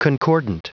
Prononciation du mot concordant en anglais (fichier audio)
Prononciation du mot : concordant